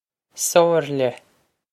Somhairle Soh-ir-lyeh
Pronunciation for how to say
This is an approximate phonetic pronunciation of the phrase.